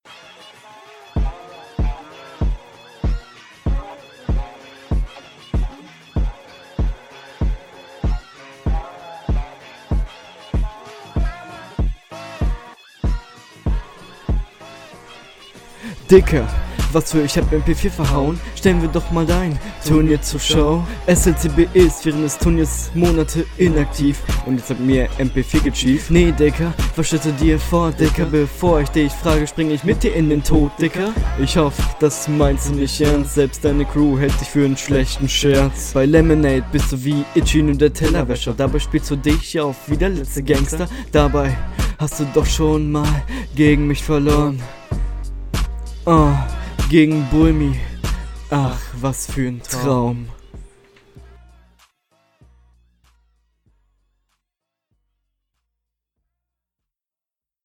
Joo cooler Beat auf jeden!